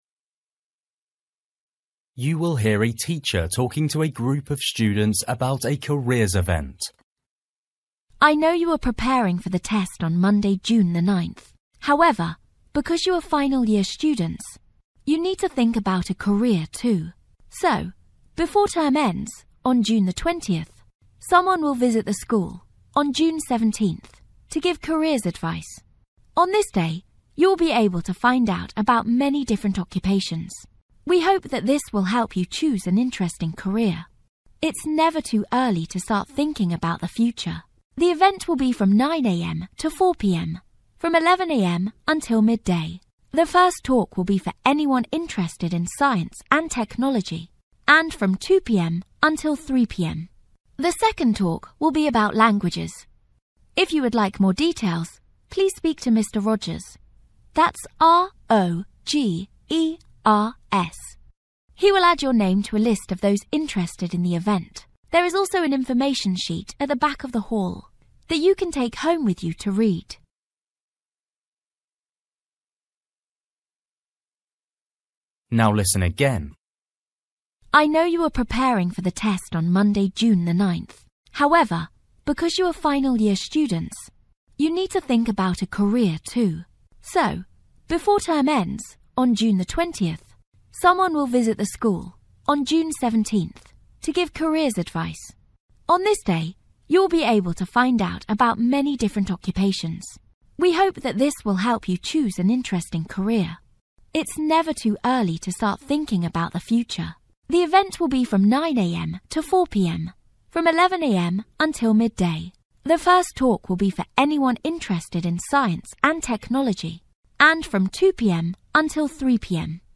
You will hear a teacher talking to a group of students about a careers event.